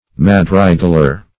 Madrigaler \Mad"ri*gal*er\, n.
madrigaler.mp3